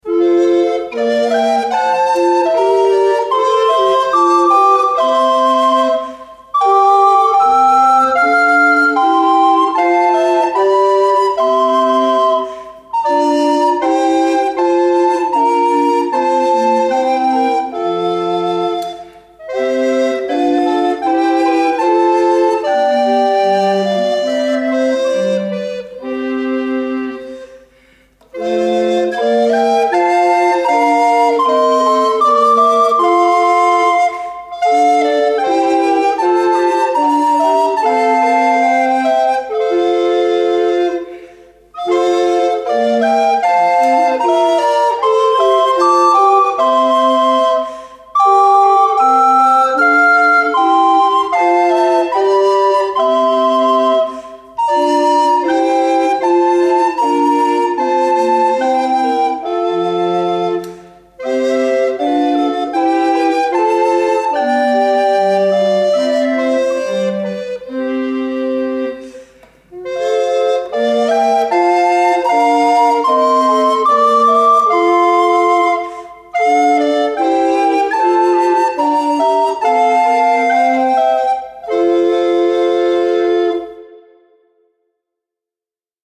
Sopran/Alt
Tenor/Bass
Tonaufnahme in Meilen